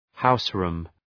Shkrimi fonetik {‘haʋs,ru:m}